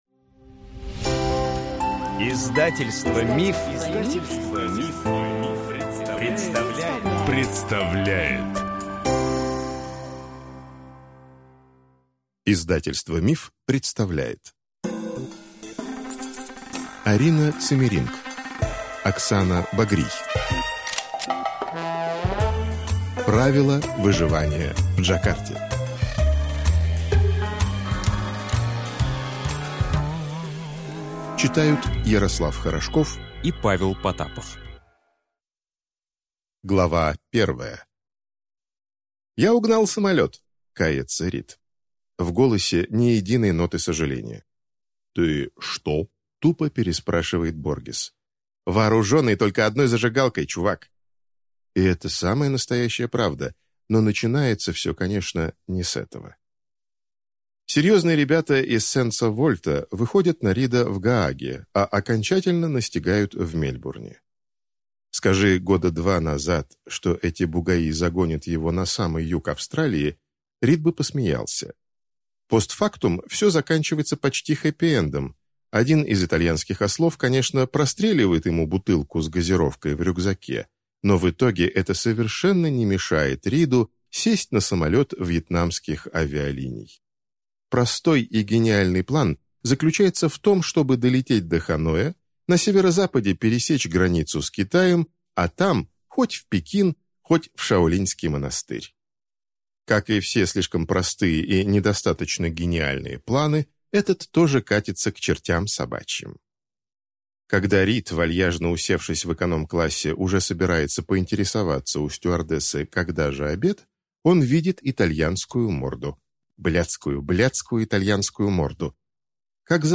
Аудиокнига Правила выживания в Джакарте | Библиотека аудиокниг